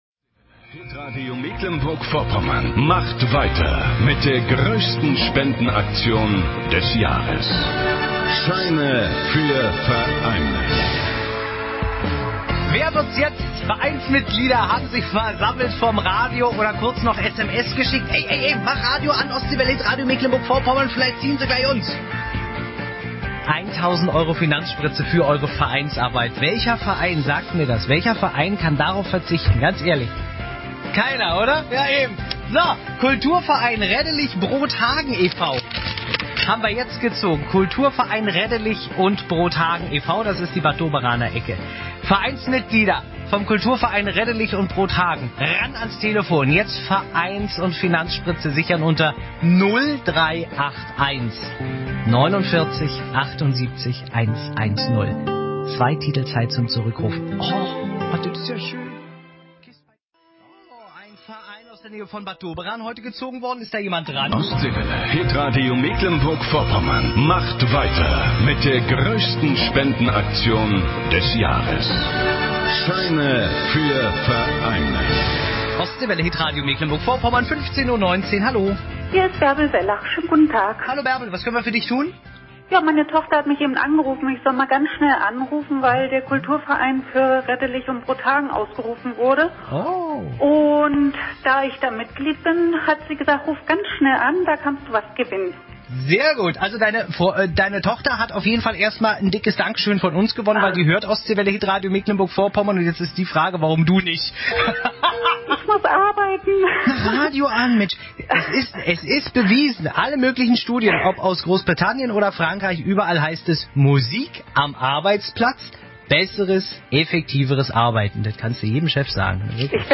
Mitschnitt bei HIT-RADIO MV